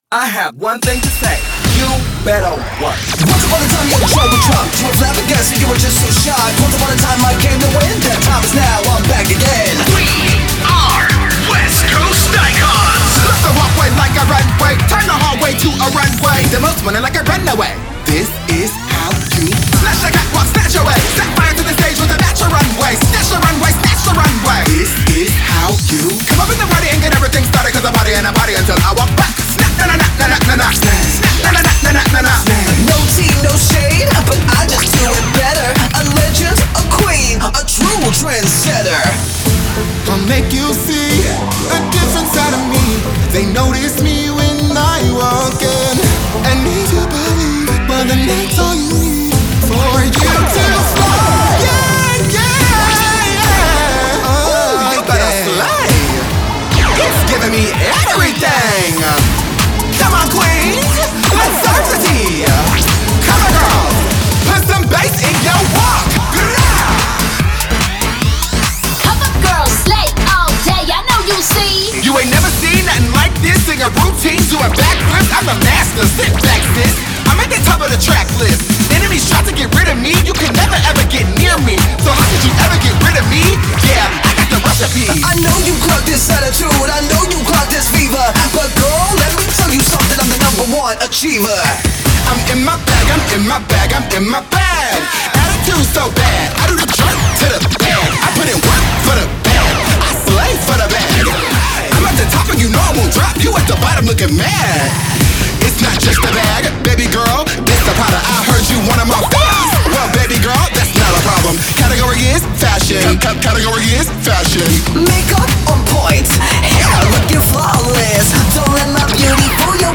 # Vocalists – 4